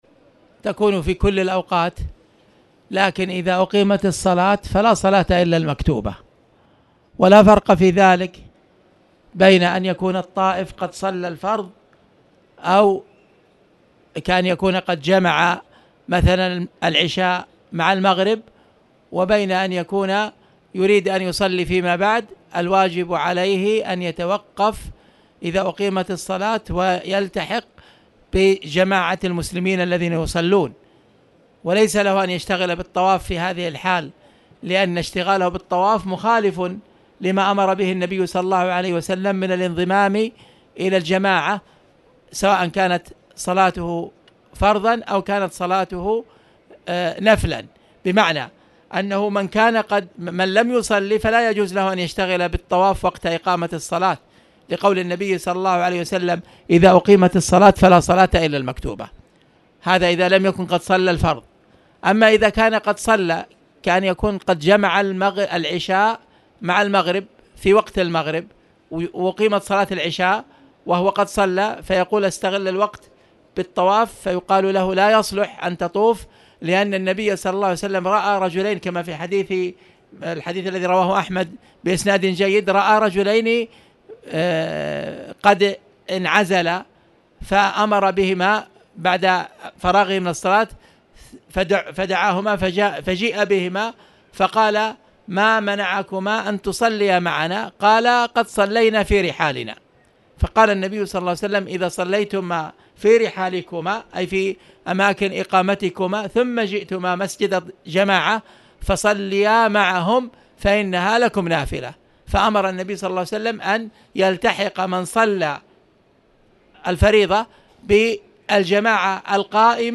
تاريخ النشر ٢٩ ربيع الثاني ١٤٣٨ هـ المكان: المسجد الحرام الشيخ